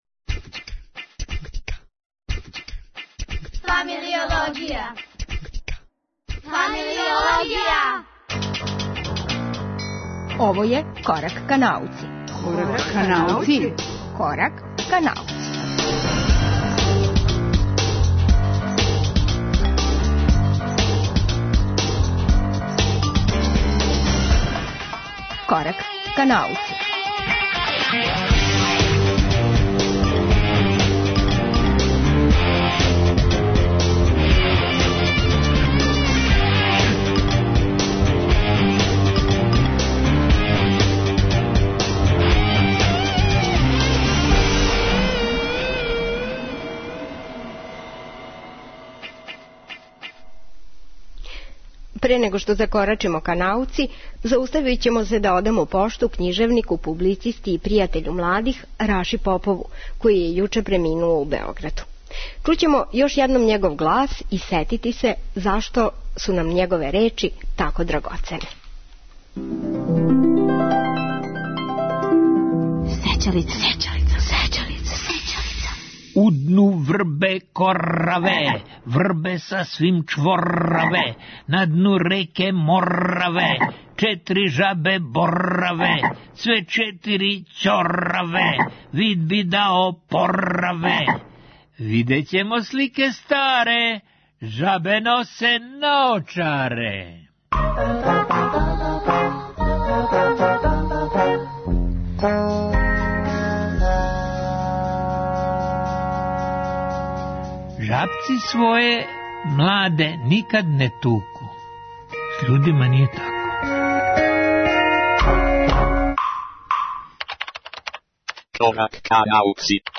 Емисију реализујемо из Новог Сада и замислили смо је као звучну позивницу за оне који би се радо придружили биолозима на некој од шездесетак радионица, на предавањима или филмским пројекцијама.